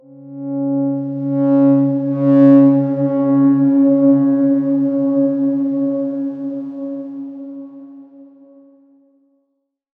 X_Darkswarm-C#3-mf.wav